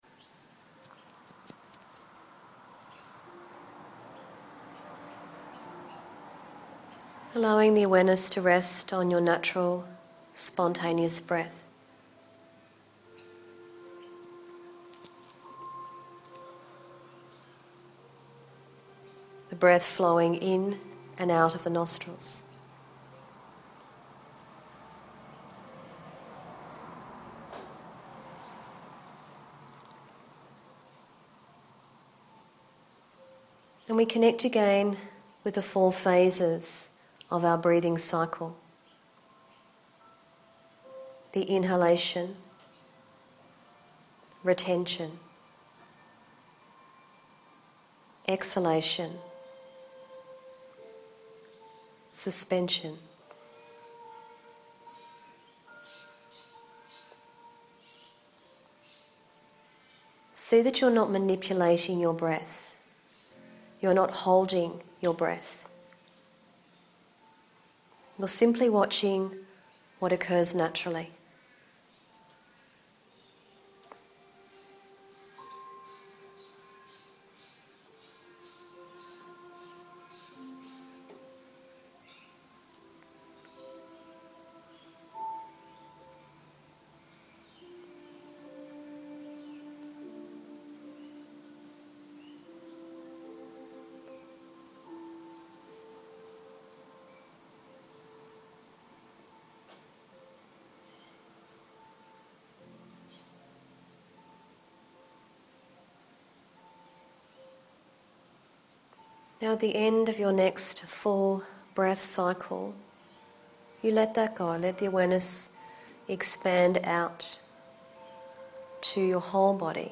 It is important to find ways to fill yourself up, so that when you give, you give from your overflow. I have attached a short voice recording (about 18 minutes in total) taken from one of my regular yoga classes. The recording ends with around 9 minutes of quiet piano music.